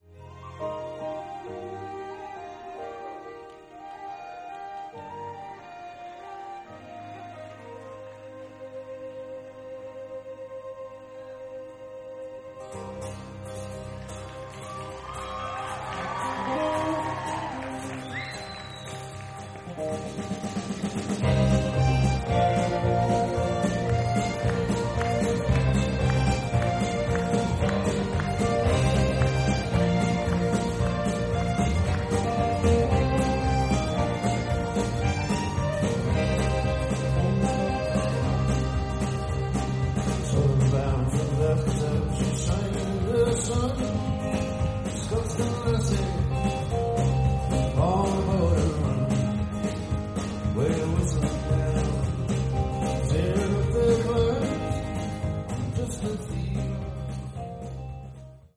Source: Beyerdynamic MC930 > Kind Kables > Fostex FR2LE
Place: Temple Hoyne Buell Theater, Denver, CO, USA